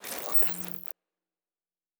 pgs/Assets/Audio/Sci-Fi Sounds/Electric/Device 10 Stop.wav at master
Device 10 Stop.wav